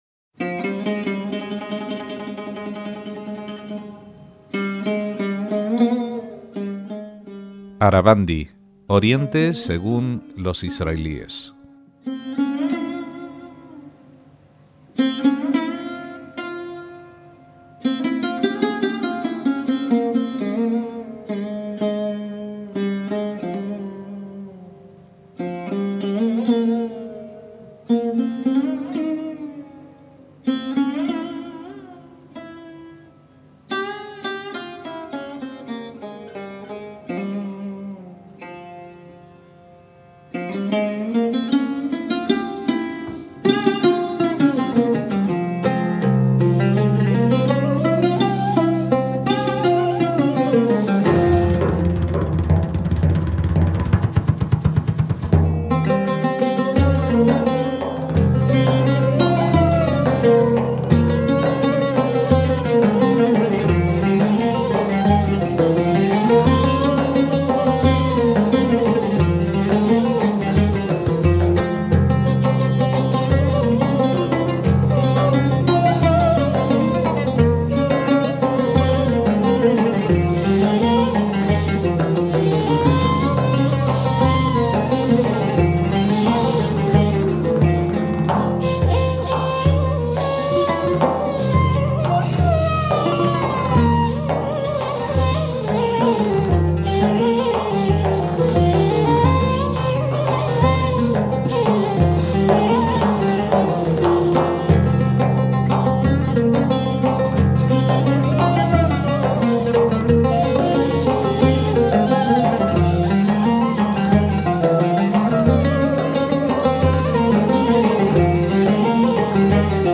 violín sarod
laúd árabe
flauta oblicua ney